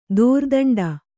♪ dōrdaṇḍa